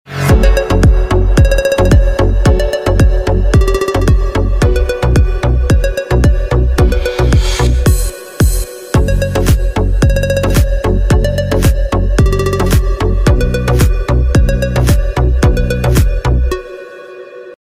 • Категория: Клубные рингтоны